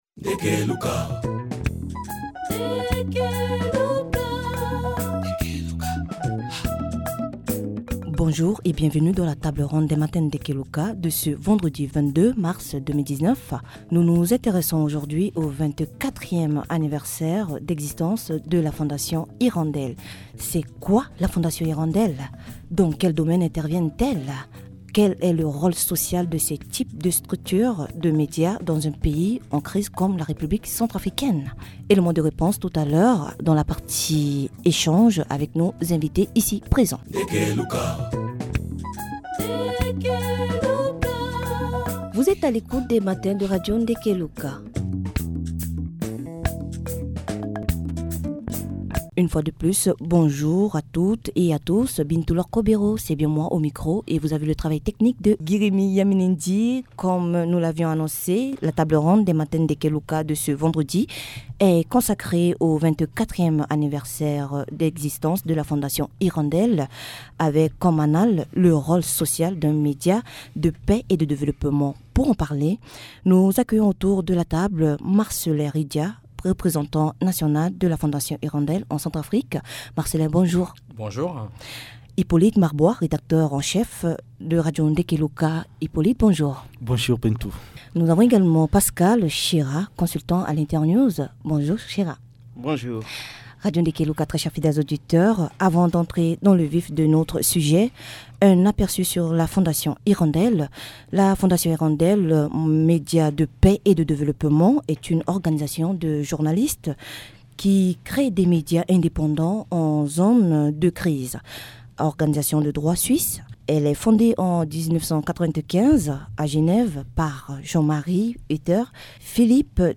Le 24ème anniversaire de la Fondation Hirondelle est le thématique abordée dans la table Ronde des Matins de Ndeke Luka.